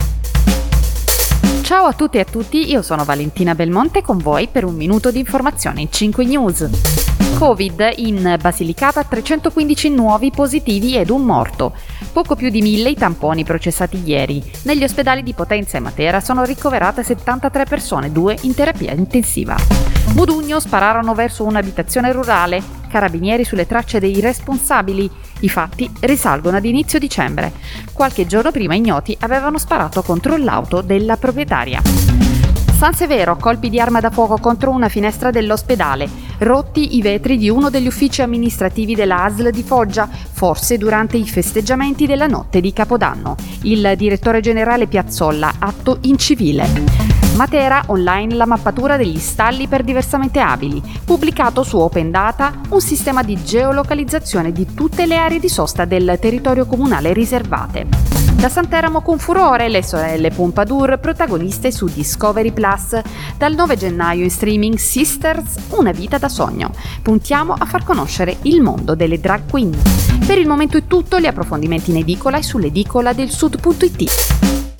Giornale radio alle ore 19